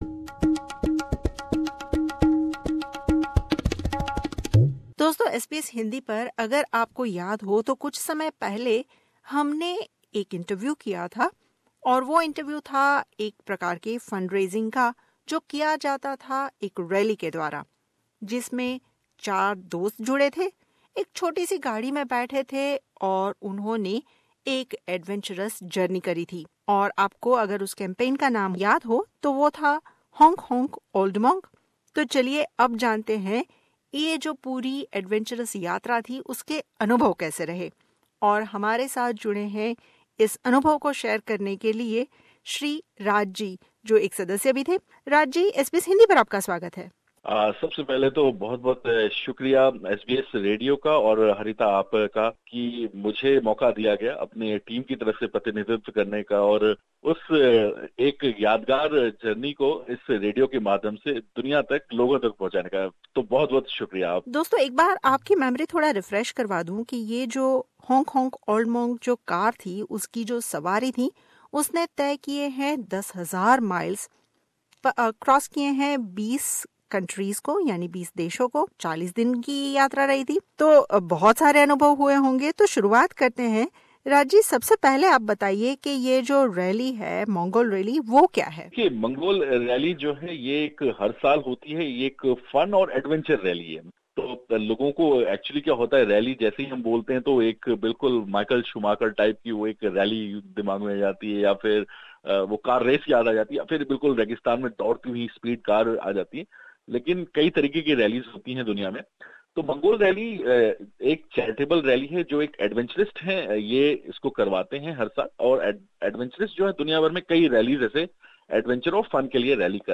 On 16th July 2017, Team Honk Honk Old Monk began their journey from London covering approx 16,000 KM from London to Mongolia. Five Indian origin Australian- Newzealander friends drove half the world in one tiny car.